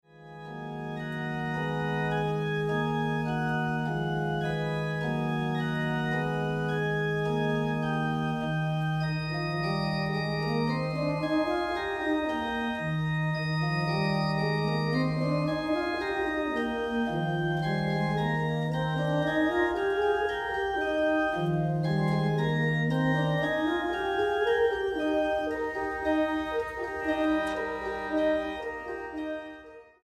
ópera en 4 actos